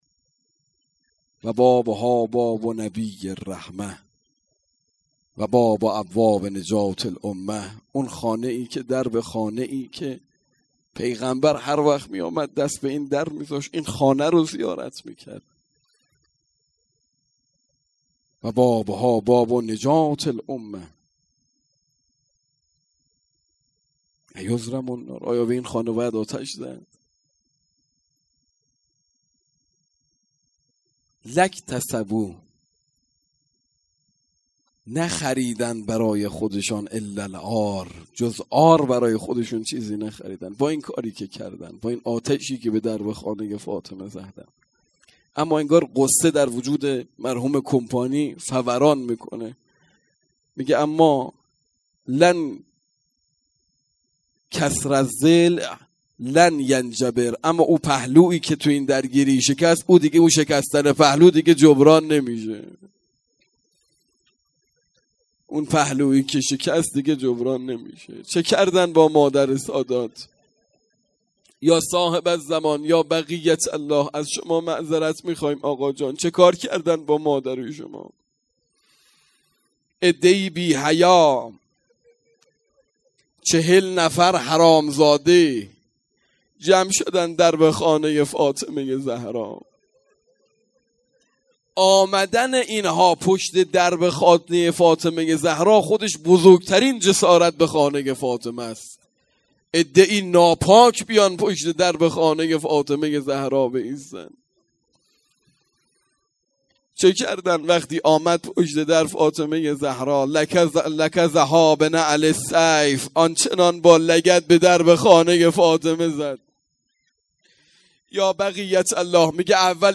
مراسم شهادت حضرت فاطمه زهرا سلام الله علیها آبان ۱۴۰۳